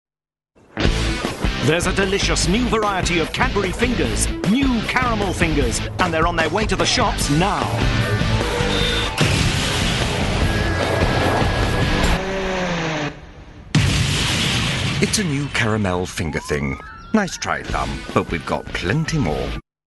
Voice Reel
James Dreyfus - Caramel Fingers - Bold, Confident, Animated
James Dreyfus - Caramel Fingers - Bold, Confident, Animated.mp3